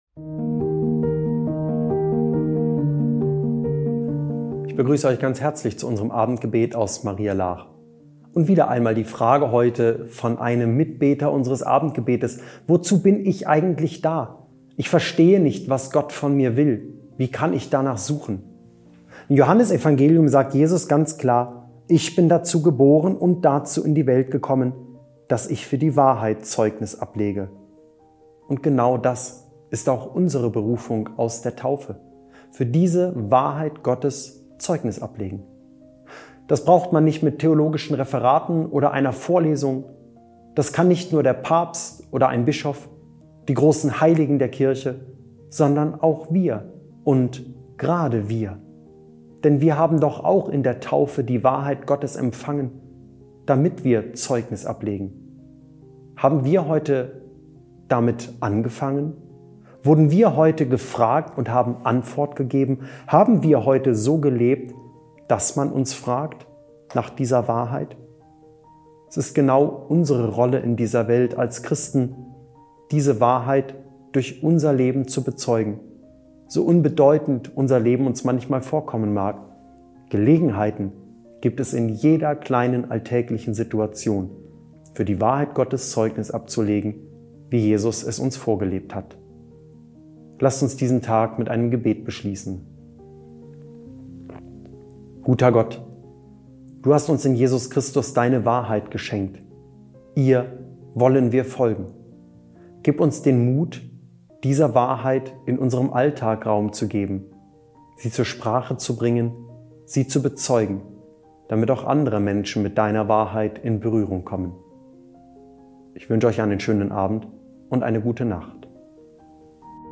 Abendgebet – 27. Dezember 2025